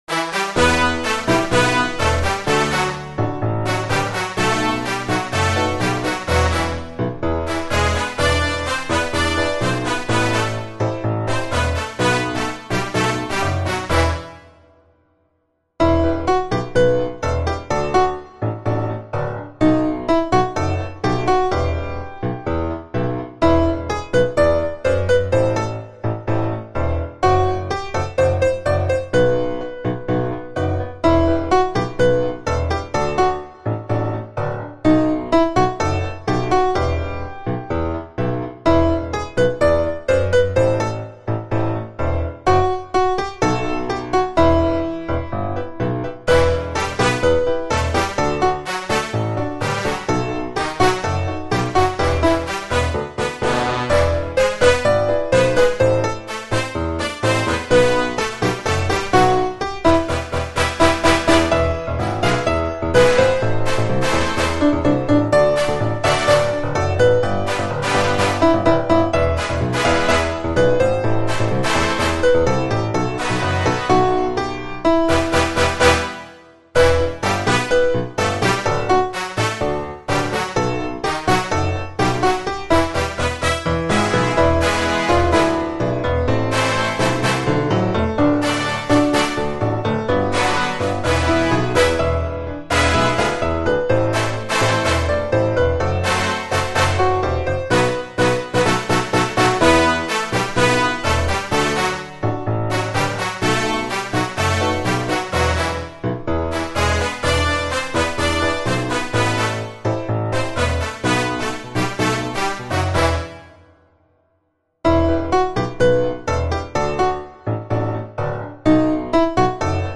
兼具很有品味的琴聲旋律
流畅的旋律，动听的声音，谢谢楼主分享.